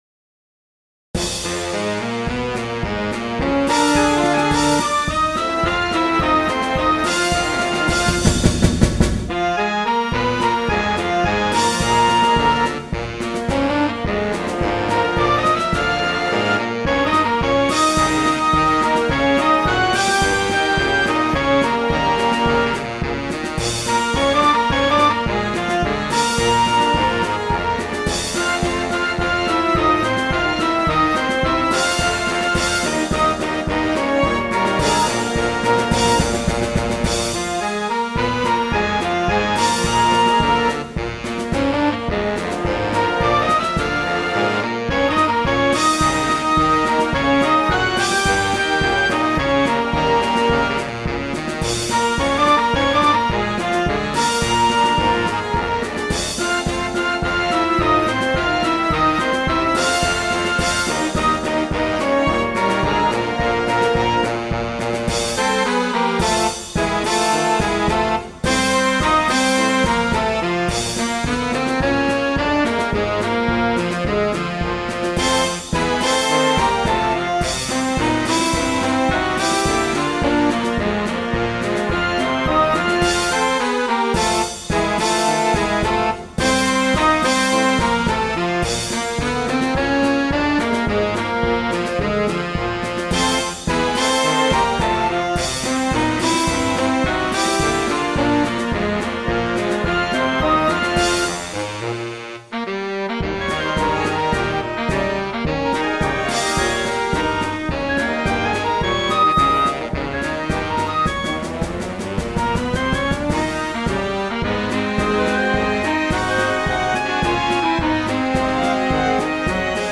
Sei marce per banda scaricabili gratuitamente.